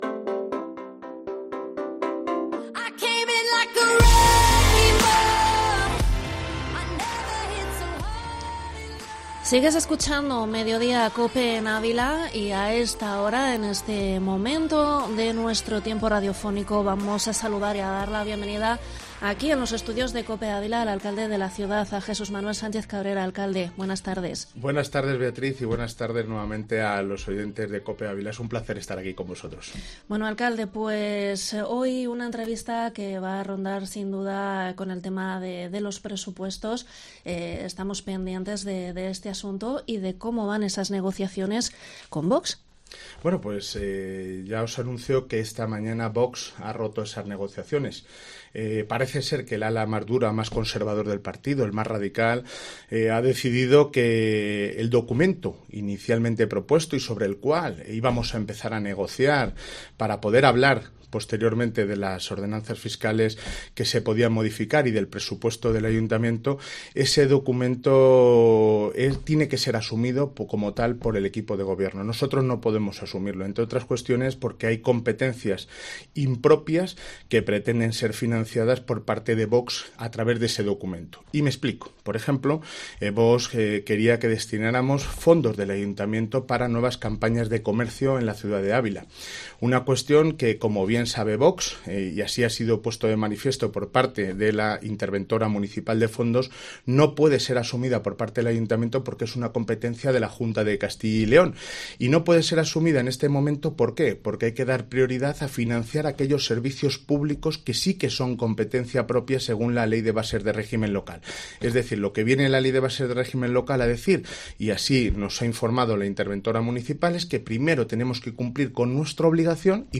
ENTREVISTA al alcalde de Ávila, Jesús Manuel Sánchez Cabrera